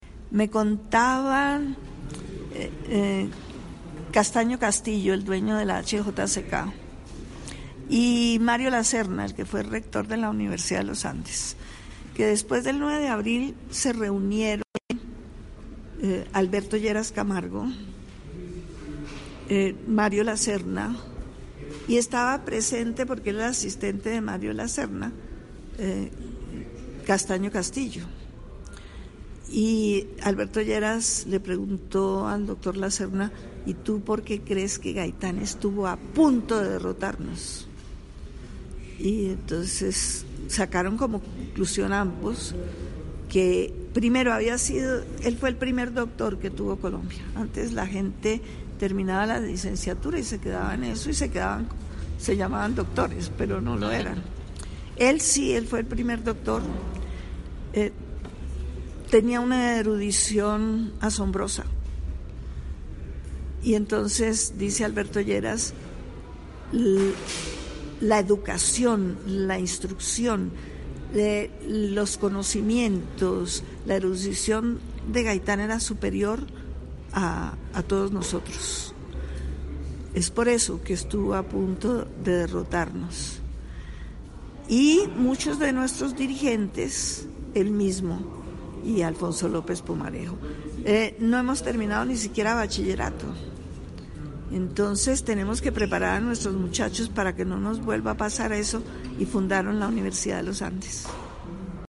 Su hija Gloria Gaitán Jaramillo, quien tenía 10 años cuando perdió a su padre, participó en el Foro que la Universidad Católica de Colombia realizó a Gaitán, como un homenaje al legado político que dejó al país.